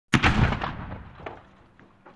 HOLA EXPLOSION 2
Ambient sound effects
hola_explosion_2.mp3